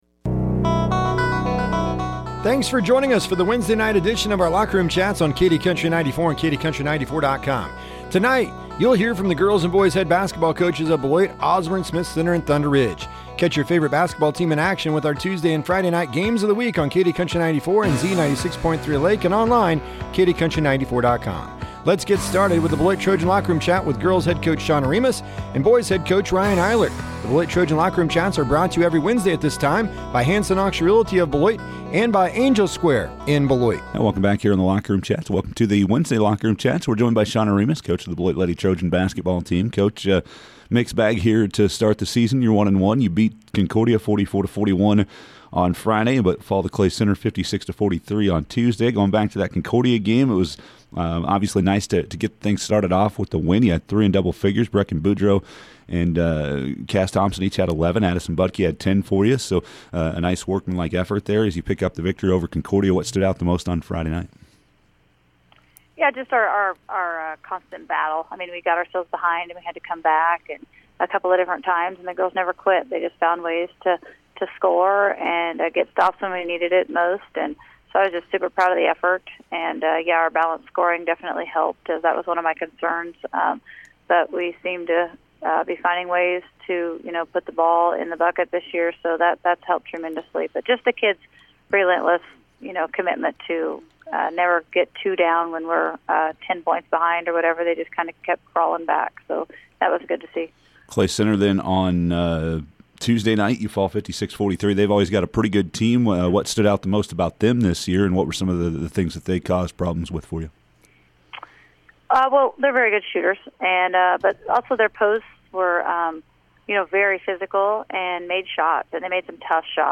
talks with the head basketball coaches